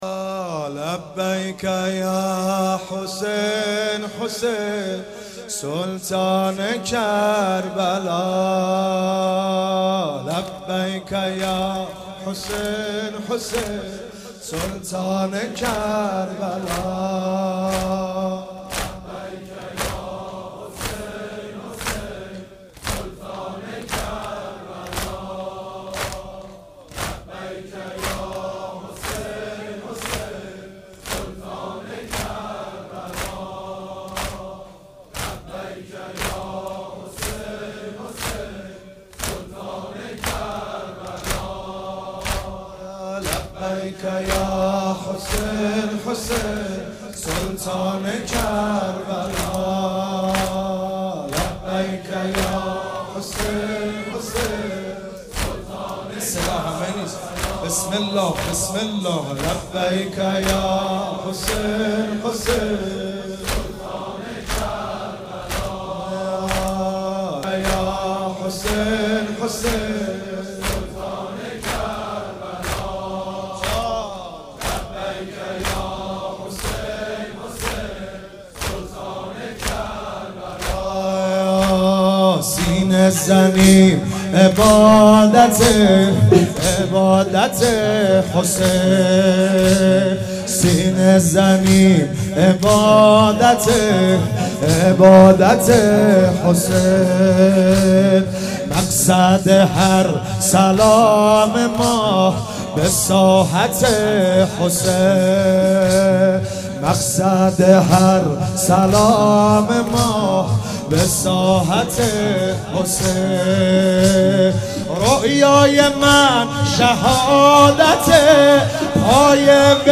نوحه
دانلود مداحی جدید محرم 94